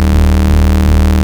BUCHLA D#2.wav